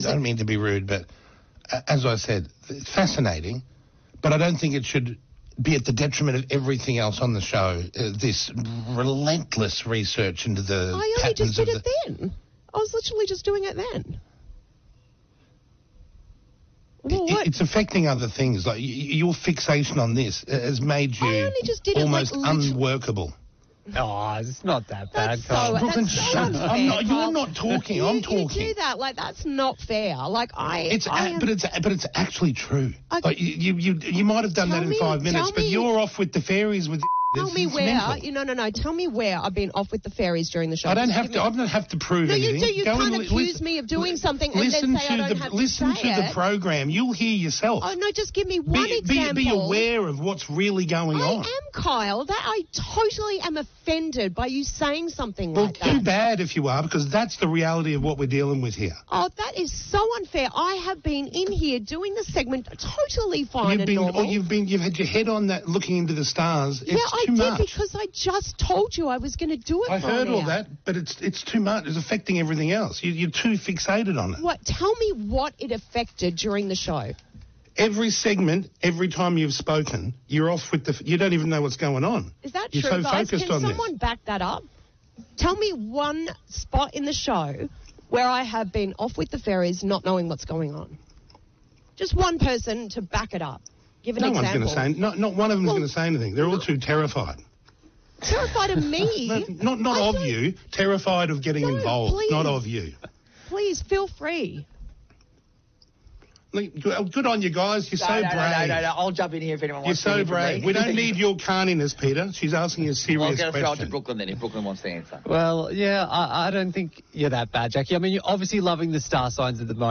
Kyle Sandilands took his co-host Jackie ‘O’ Henderson to task on air on Friday morning for her lack of professionalism, claiming that everyone on their team has mentioned it to him at some point in the past month.
The on-air fight during the pair’s Kiis breakfast show erupted as Henderson was looking at Andrew Mountbatten-Windsor’s star chart on her phone during a segment.
The spat went on for five minutes, during which time various team members attempted to shut it down.
Henderson was audibly hurt, and close to tears during the argument.